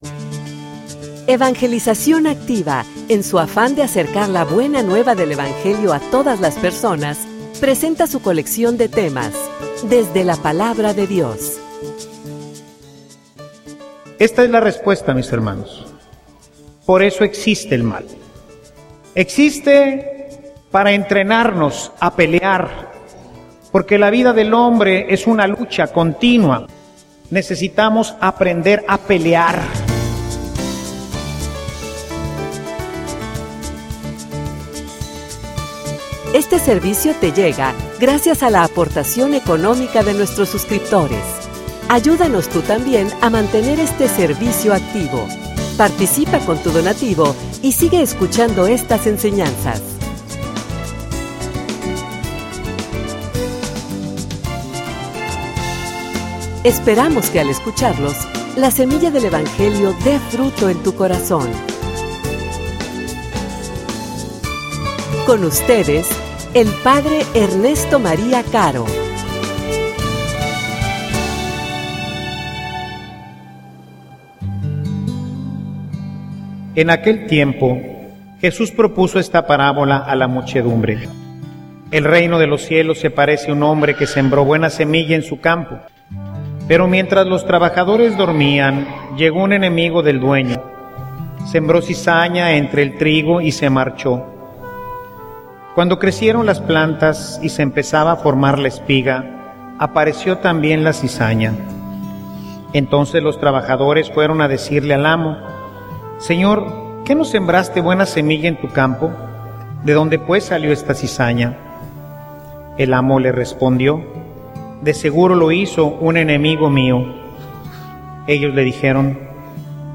homilia_Los_dos_caminos.mp3